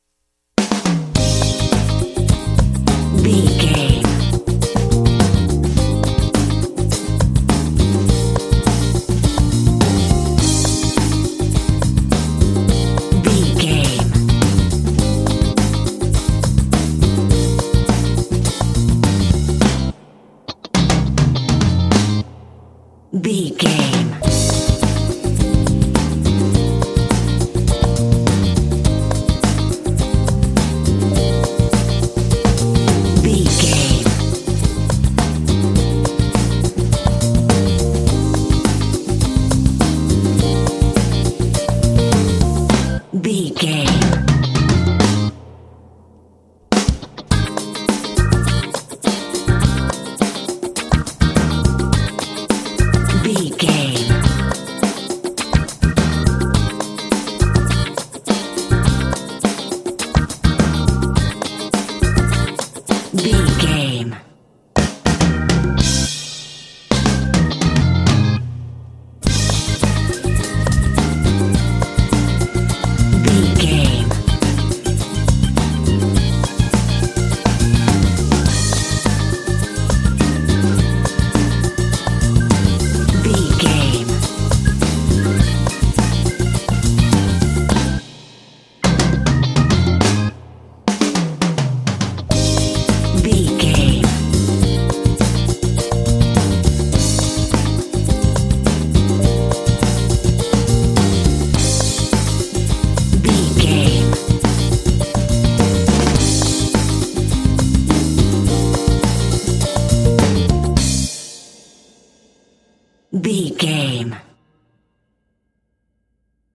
Uplifting
Aeolian/Minor
groovy
driving
energetic
electric organ
electric piano
drums
bass guitar
electric guitar